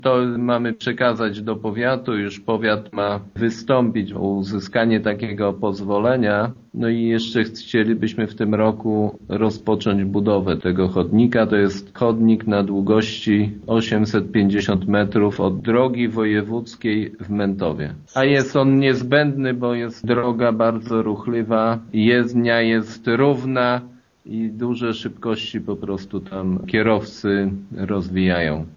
Na sesji w czwartek 12 maja rada zadecydowała, że „przekaże Powiatowi Lubelskiemu dokumentację, potrzebną do uzyskania pozwolenia na budowę chodnika przy drodze Mętów – Prawiedniki” – informuje wójt Jacek Anasiewicz: